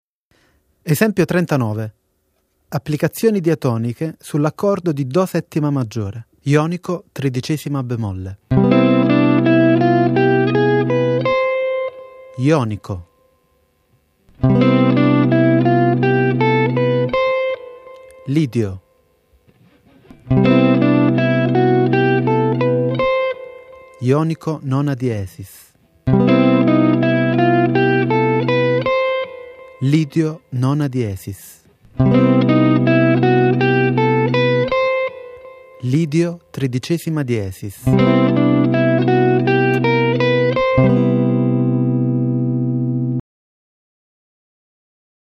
Soluzioni modali su Cmaj7
Nome del modo: Ionico b13